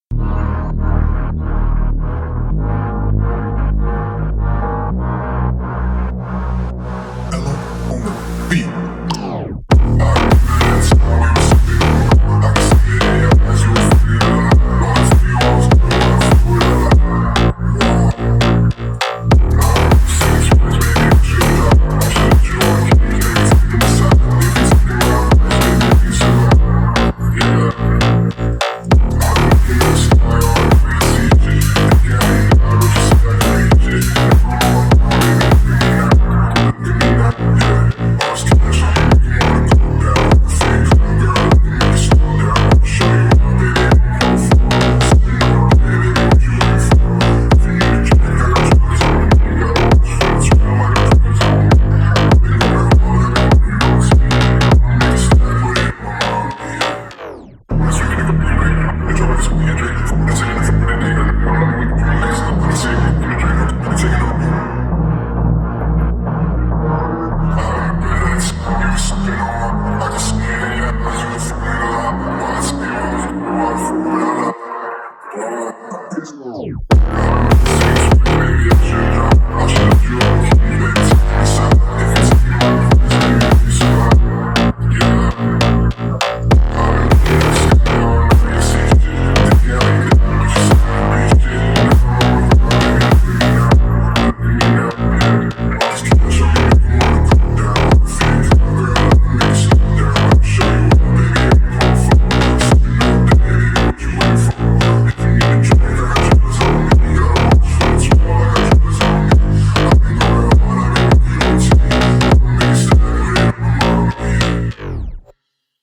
это трек в жанре хип-хоп с элементами эмо и меланхолии.